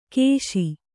♪ kēśi